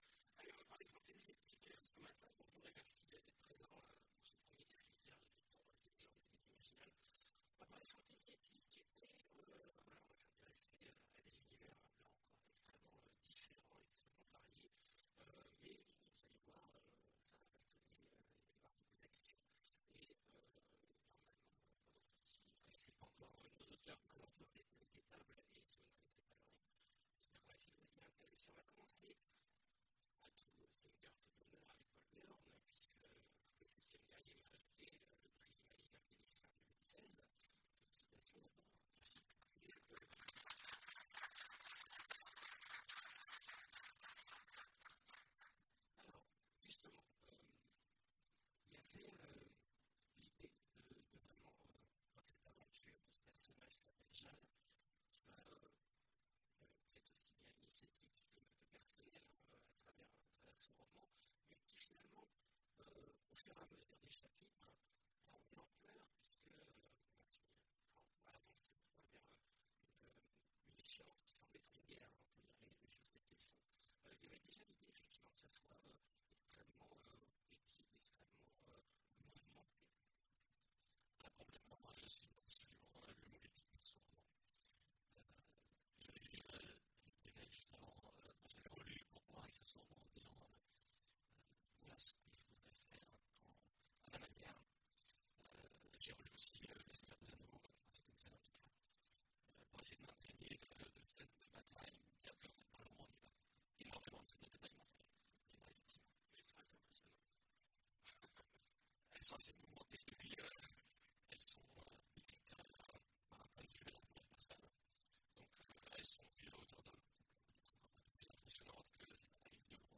Imaginales 2016 : Conférence Indémodables héros…